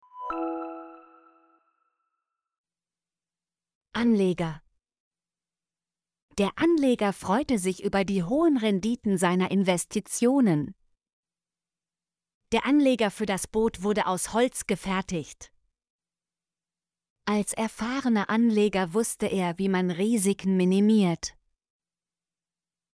anleger - Example Sentences & Pronunciation, German Frequency List